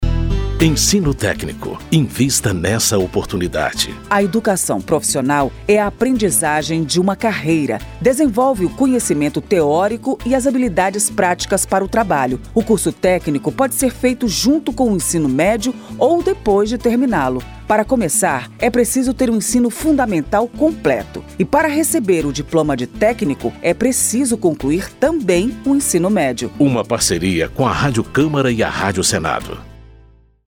Por isso, a Rádio Câmara e a Rádio Senado criaram cinco spots com informações sobre educação técnica e profissional, mostrando o valor das carreiras técnicas e incentivando o investimento nesse setor.